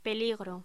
Locución: Peligro
voz